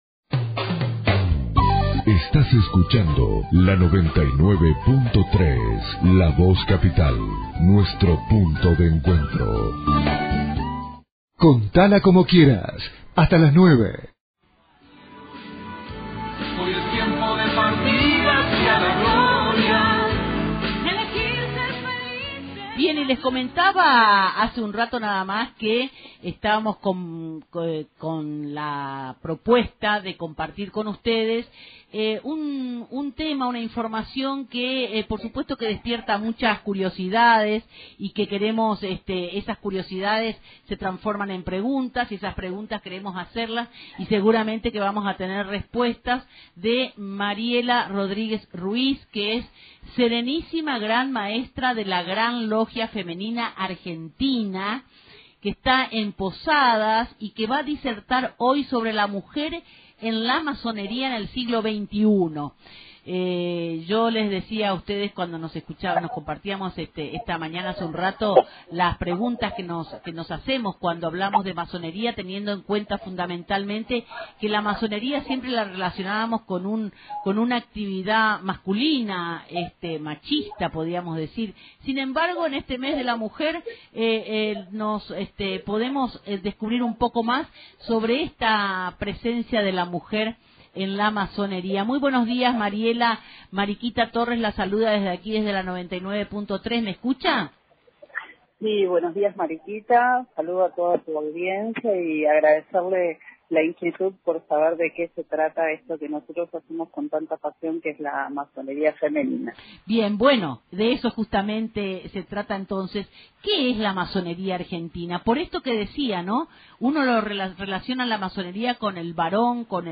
Entrevista en Radio Identidad (Posadas)